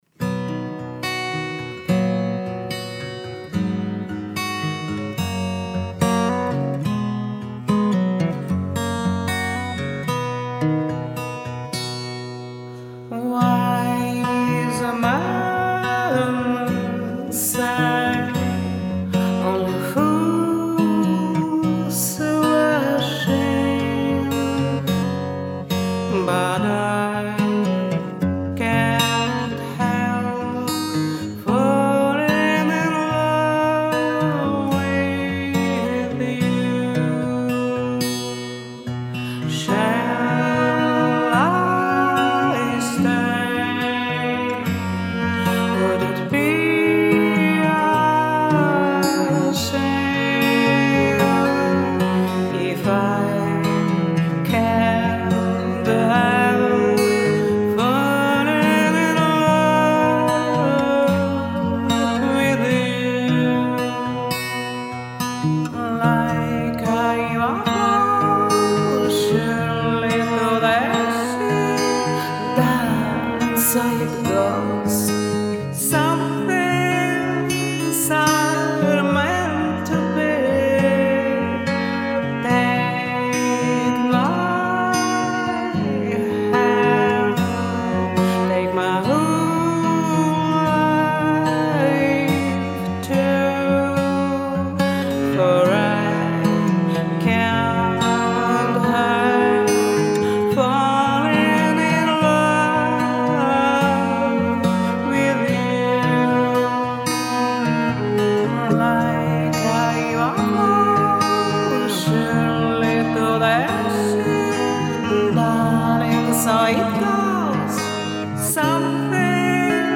У меня акустическая версия этой песни .